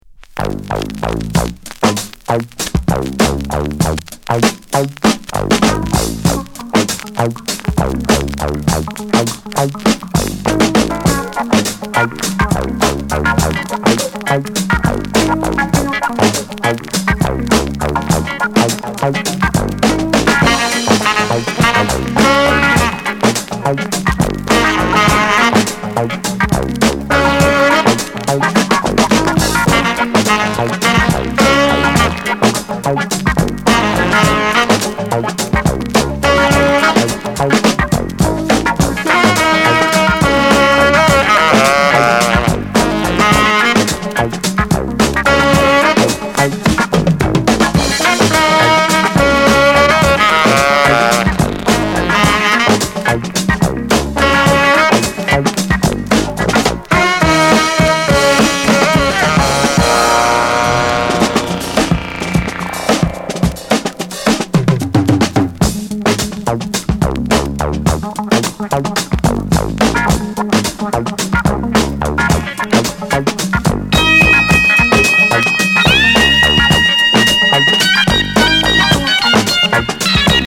Genre: Jazz